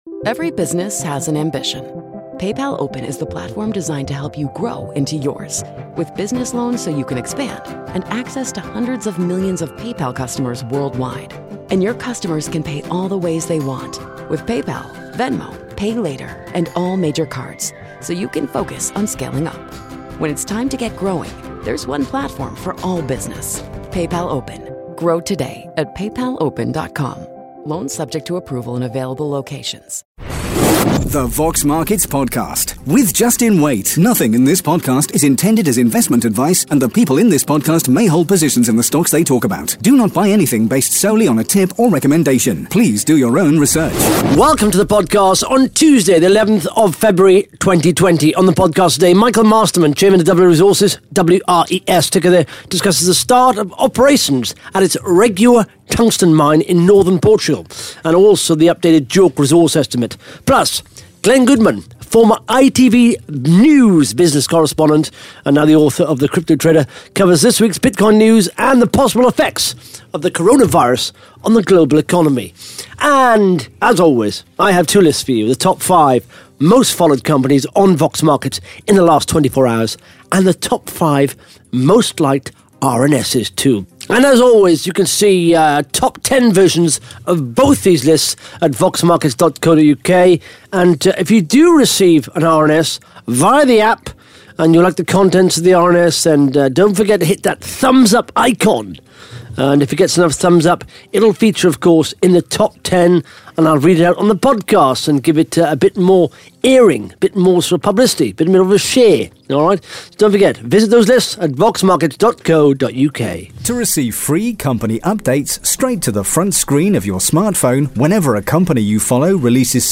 (Interview starts at 7 minutes 26 seconds) Plus the Top 5 Most Followed Companies & the Top 5 Most Liked RNS’s on Vox Markets in the last 24 hours.